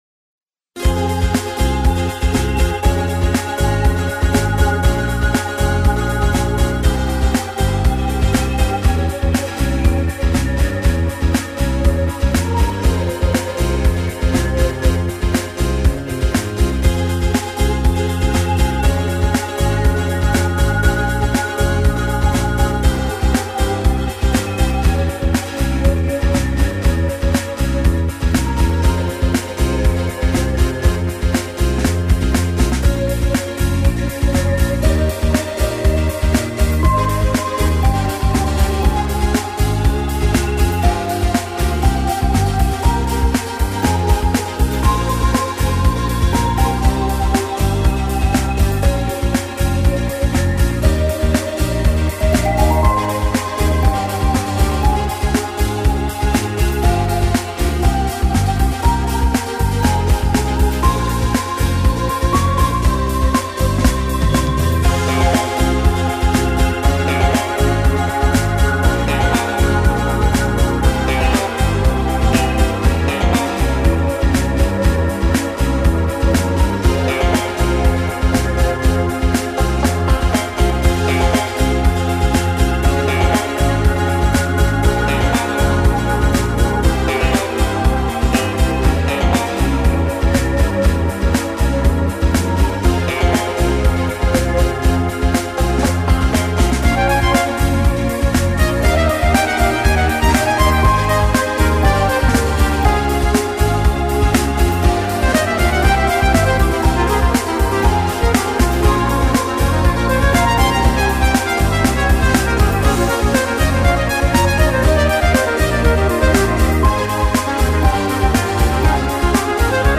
Поп (5228)